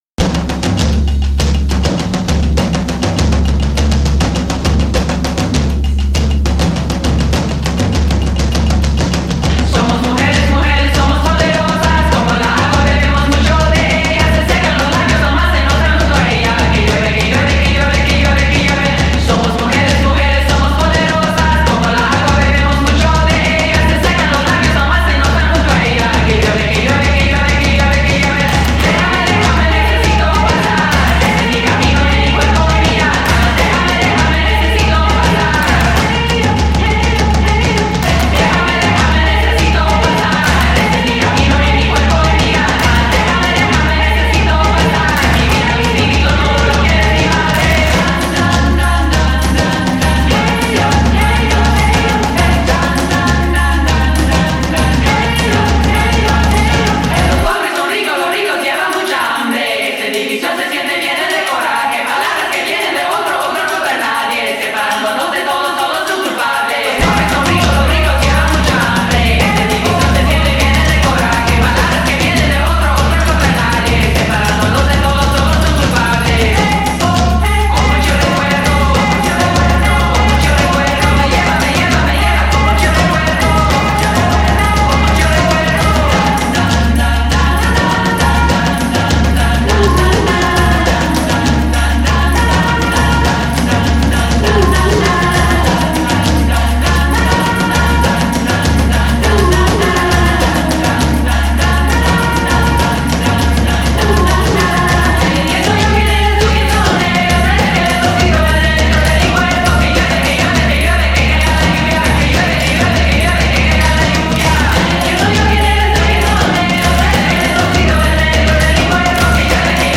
Portland-based Mexican-American band
a driving anthem against misogyny